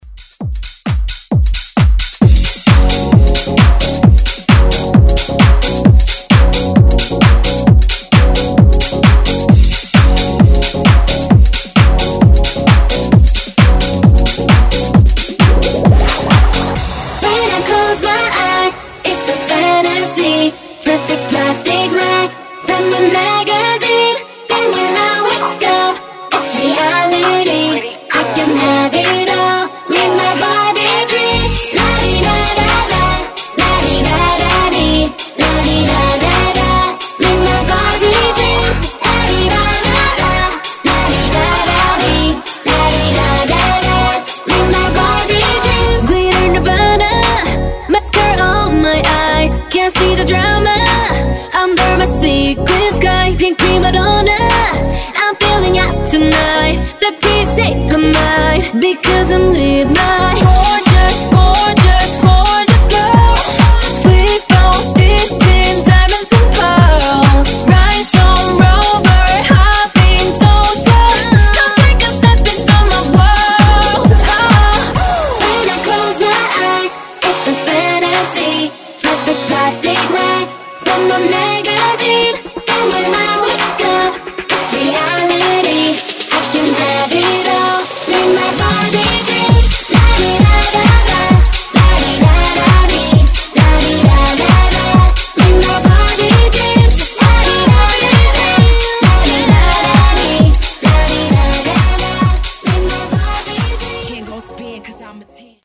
Genres: DANCE , RE-DRUM , TOP40
Clean BPM: 132 Time